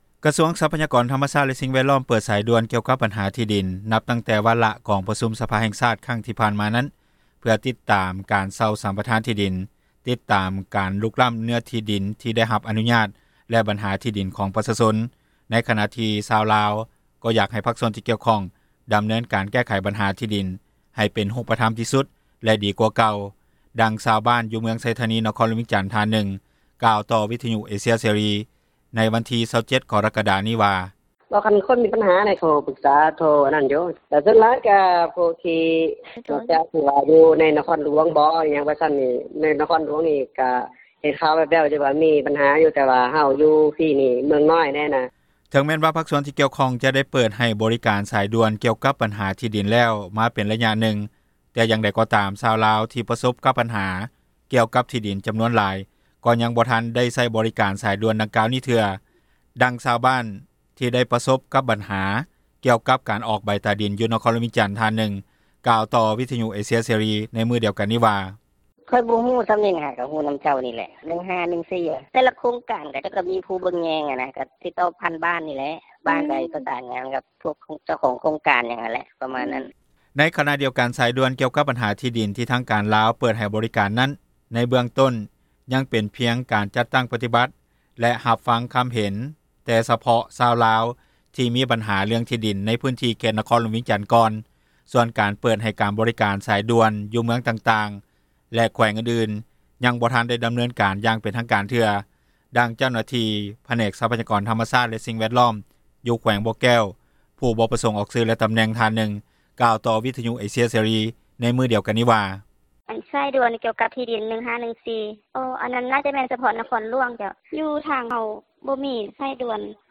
ດັ່ງຊາວບ້ານຢູ່ເມືອງໄຊທານີ ນະຄອນຫຼວງວຽງຈັນ ທ່ານນຶ່ງກ່າວຕໍ່ ວິທຍຸເອເຊັຽເສຣີ ເມື່ອງວັນທີ 27 ກໍຣະກະດາ 2022 ນີ້ວ່າ:
ດັ່ງນັກຊ່ຽວຊານ ດ້ານກົດໝາຽ ທີ່ເຮັດວຽກກ່ຽວກັບ ທີ່ດິນໂດຍກົງທ່ານນຶ່ງກ່າວວ່າ: